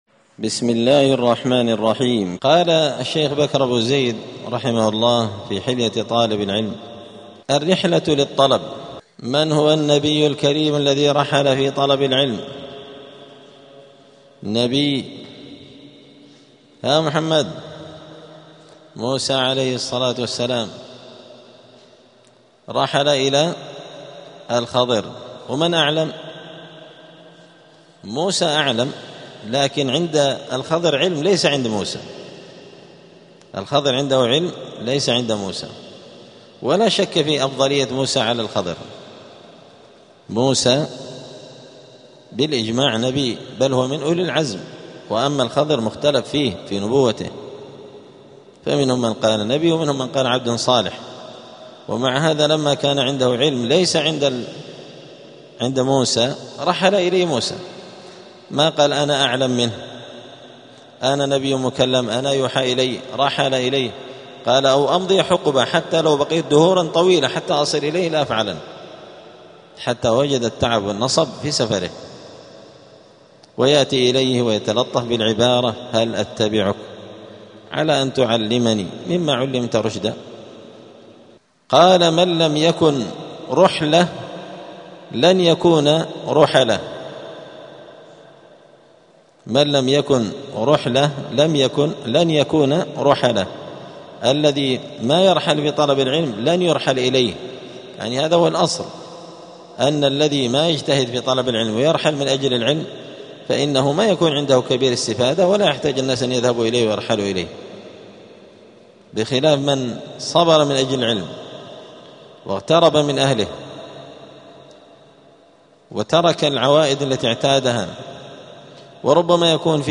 *الدرس السادس والخمسون (56) فصل آداب الطالب في حياته العلمية {الرحلة للطلب}.*
دار الحديث السلفية بمسجد الفرقان قشن المهرة اليمن